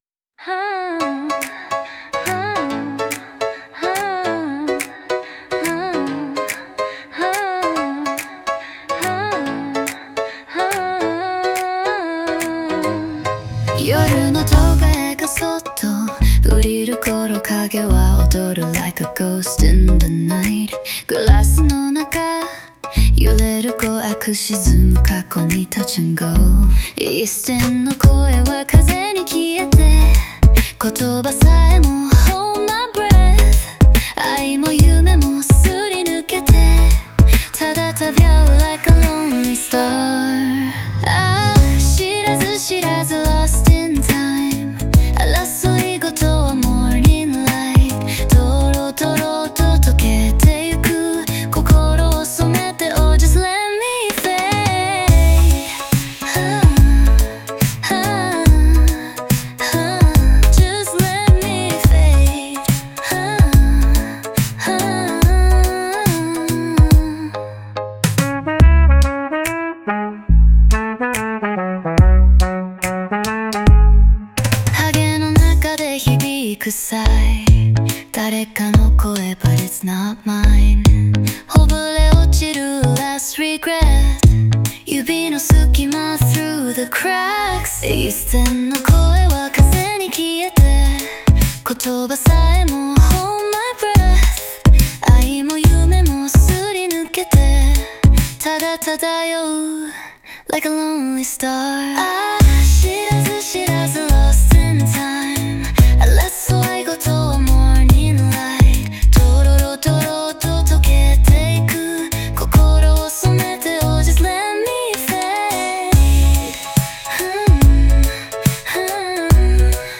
オリジナル曲♪
全体的に幻想的で退廃的な雰囲気を持ち、曖昧なまま終わることで余韻を残す構成になっている。